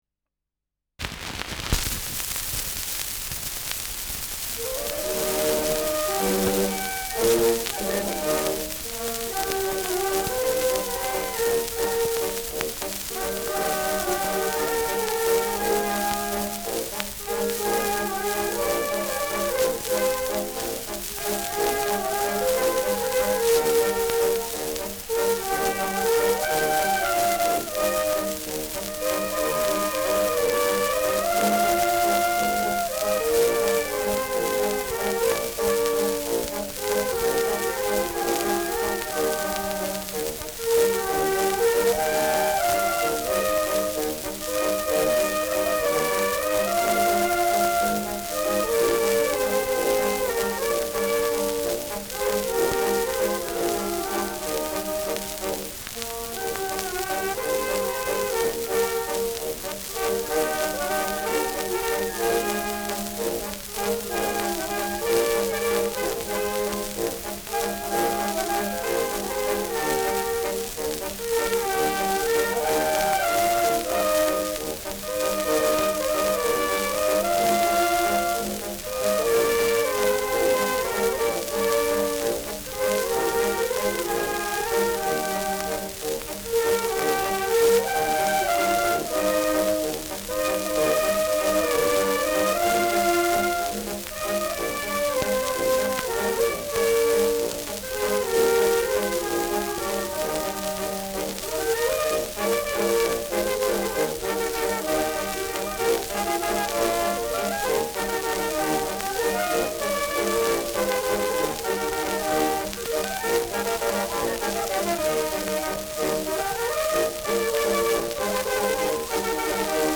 Schellackplatte
ausgeprägtes Rauschen
Stadtkapelle Fürth (Interpretation)